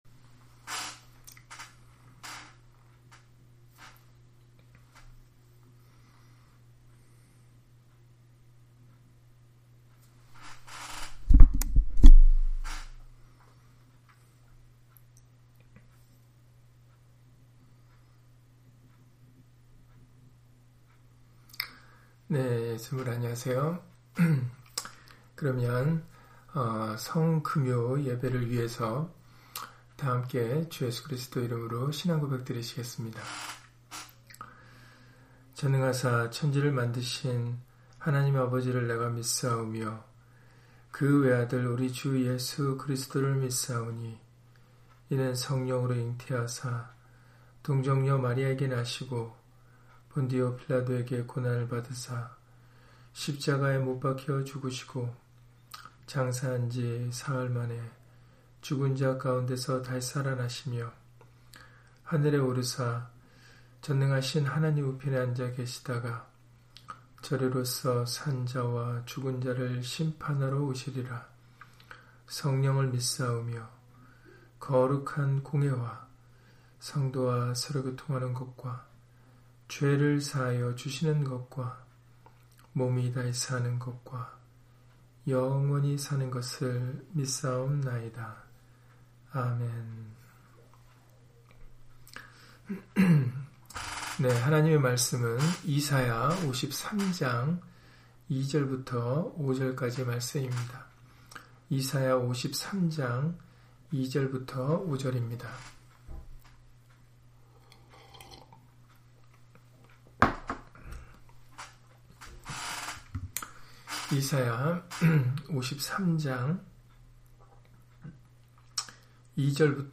이사야 53장 2-5절 [성 금요예배] - 주일/수요예배 설교 - 주 예수 그리스도 이름 예배당